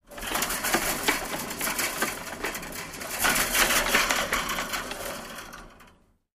fo_chainfence_rustle_02_hpx
Chain link fence is rattled. Rattle, Chain Link Fence Metallic, Chain Link Fence